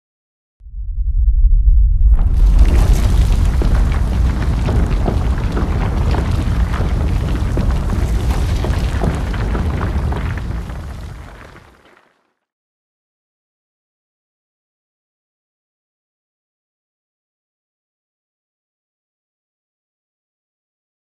دانلود آهنگ زمین لرزه 1 از افکت صوتی طبیعت و محیط
جلوه های صوتی
دانلود صدای زمین لرزه 1 از ساعد نیوز با لینک مستقیم و کیفیت بالا